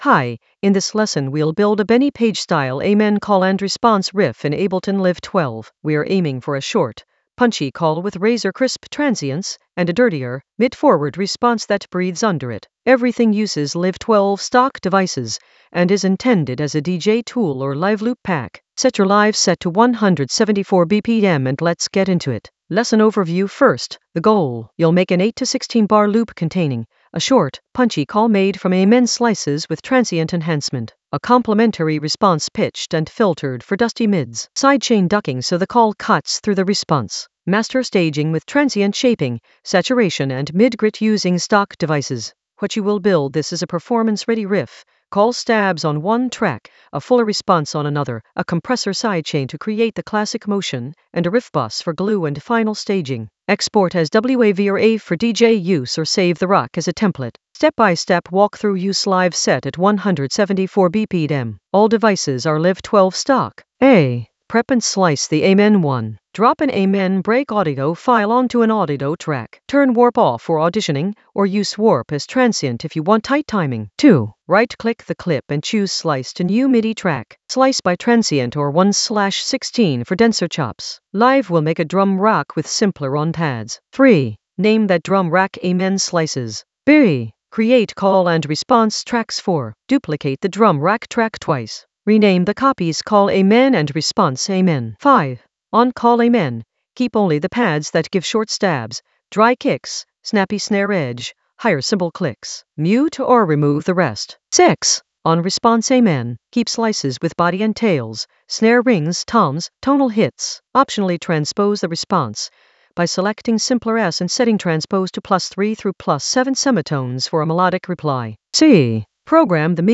An AI-generated intermediate Ableton lesson focused on Benny Page Ableton Live 12 Amen-style call-and-response riff blueprint with crisp transients and dusty mids in the DJ Tools area of drum and bass production.
Narrated lesson audio
The voice track includes the tutorial plus extra teacher commentary.